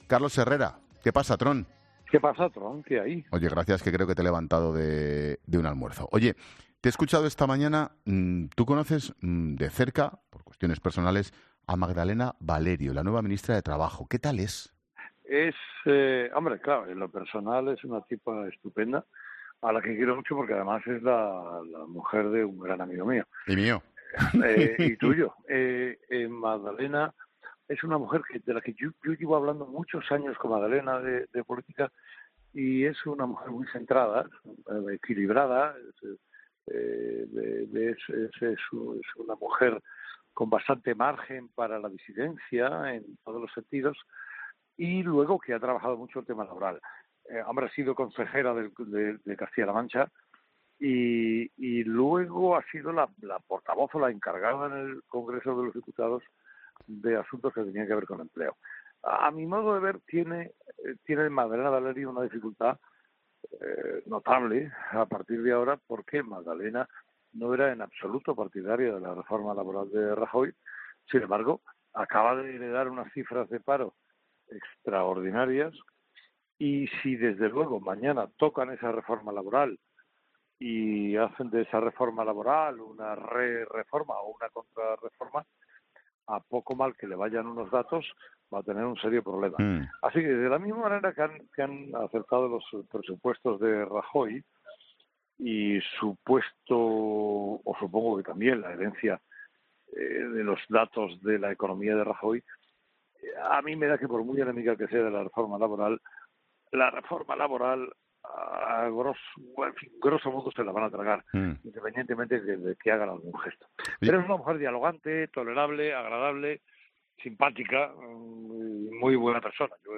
La lista de nuevos ministros se ha ido ampliando a lo largo de este miércoles y Ángel Expósito no ha querido perder la oportunidad de hablar con Carlos Herrera sobre el nuevo gobierno de Sánchez.